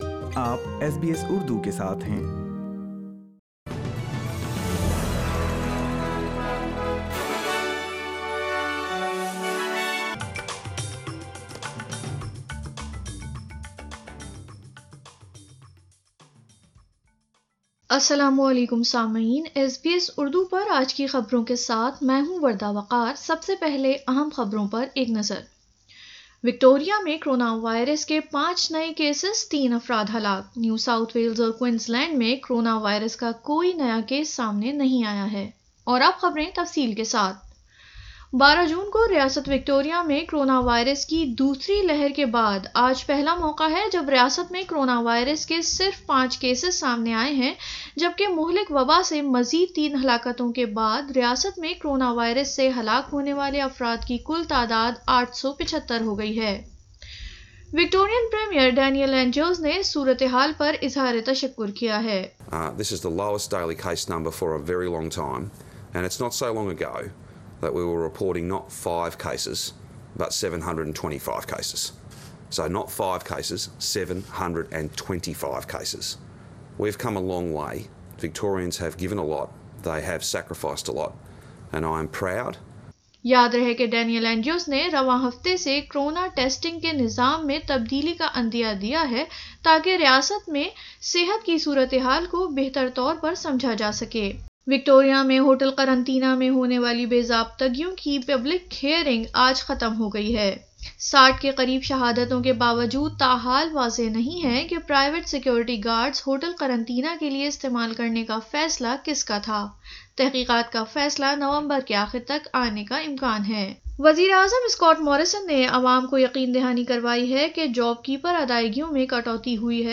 اردو خبریں 28 ستمبر 2020